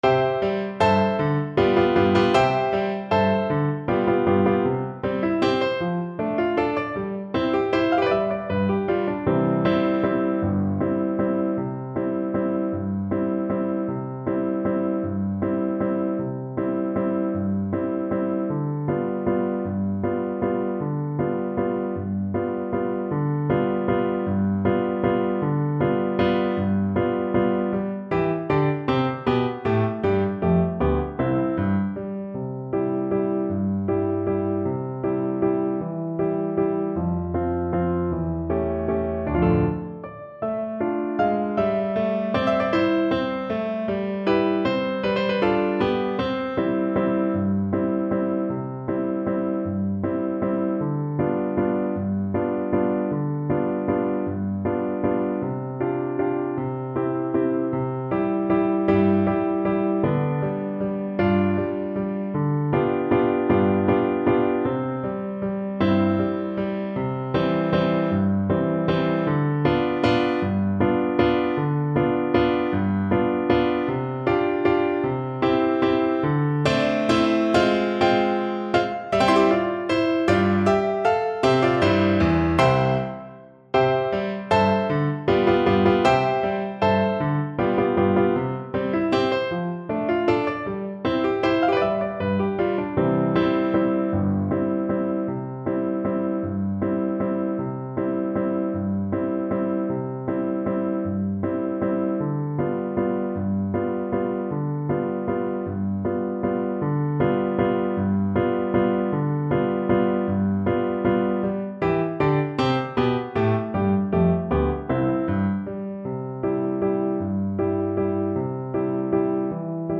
6/8 (View more 6/8 Music)
Allegretto . = c.52
Neapolitan Songs for Recorder